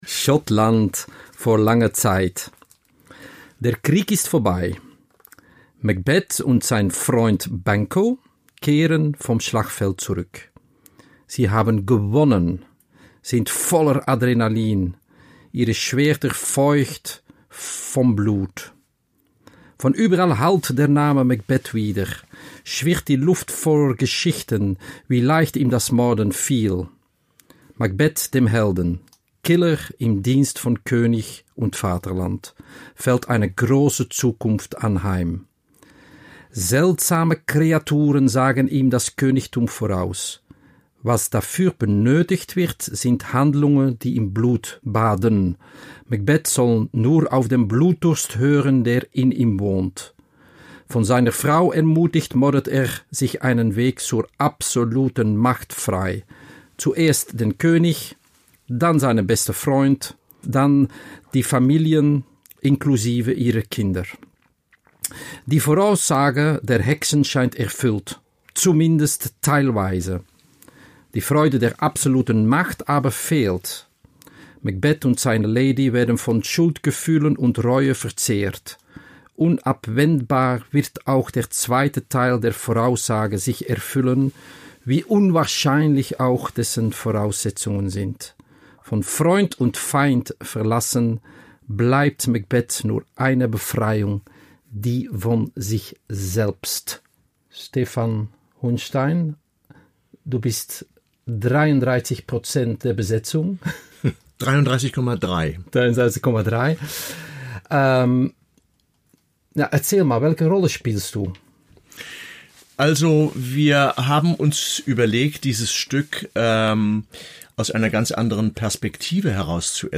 Erfahren Sie mehr über neue Inszenierungen aus dem Schauspielhaus Bochum in der neuen Episode der Talkreihe und Audioeinführung mit Künstler*innen und Dramaturg*innen der Produktion.